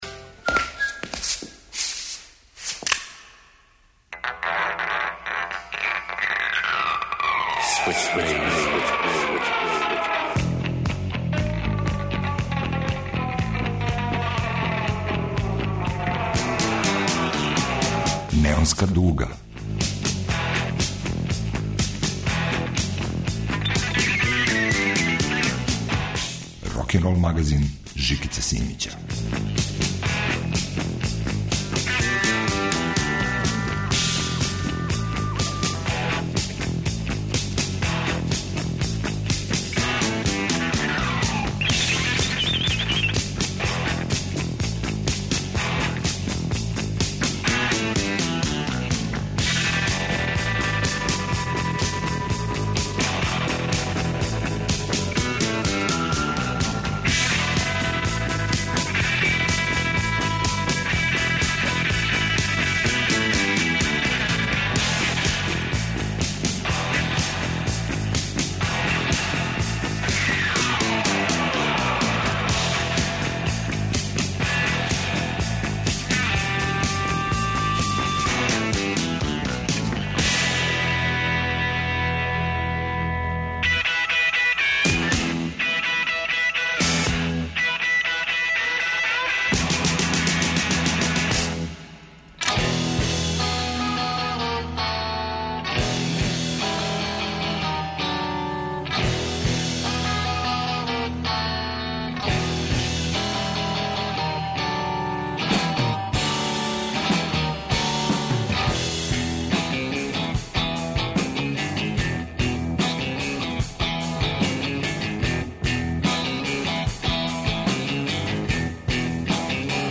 Рокенрол као музички скор за живот на дивљој страни. Вратоломни сурф кроз време и жанрове. Старо и ново у нераскидивом загрљају.